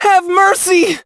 hotshot_die_03.wav